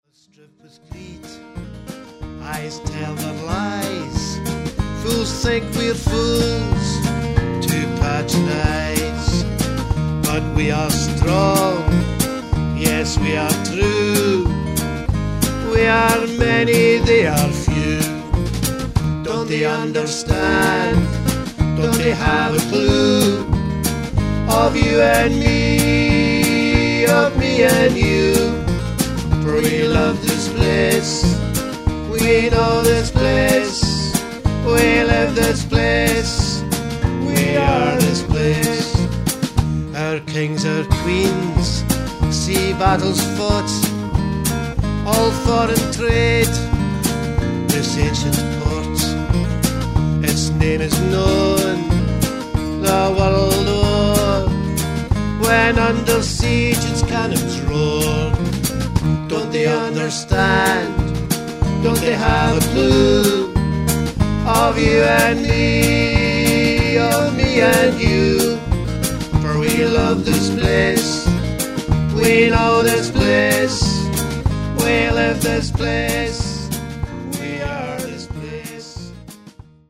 vocal, guitar, bouzouki, harmonica and 5 string banjo
fiddle
bass guitar and keyboards
drum-kit